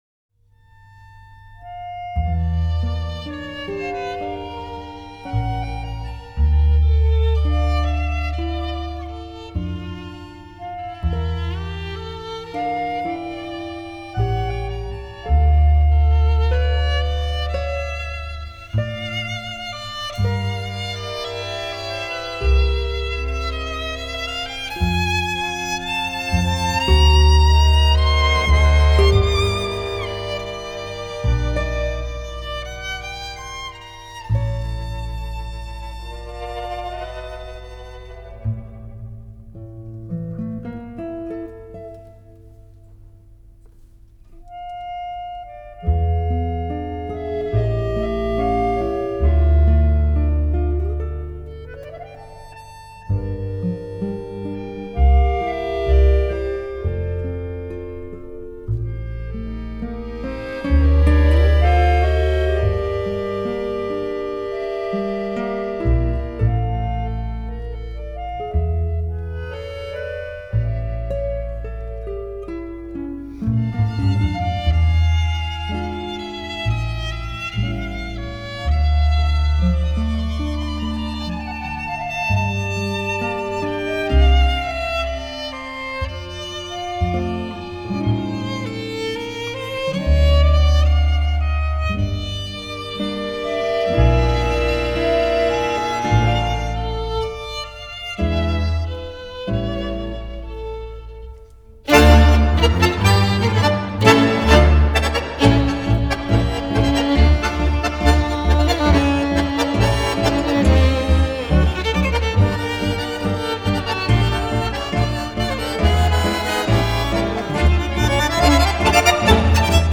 который состоял из баяна, скрипки, гитары и контрабаса.